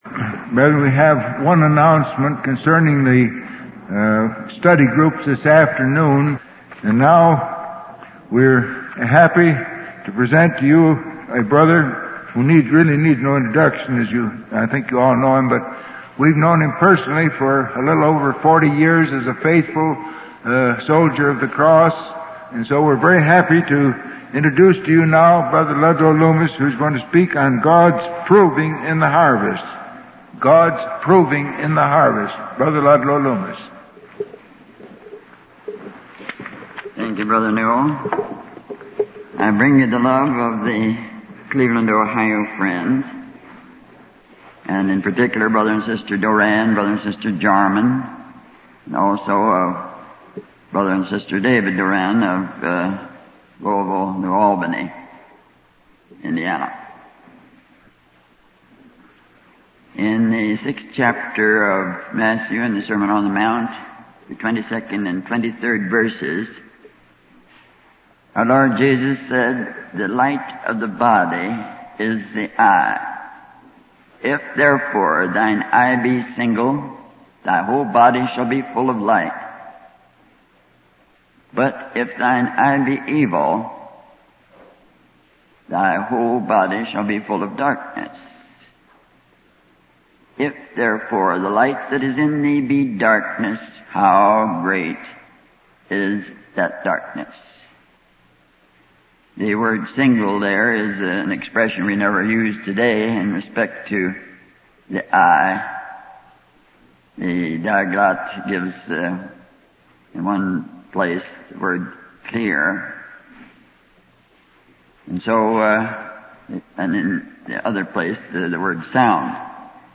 From Type: "Discourse"
Denver Convention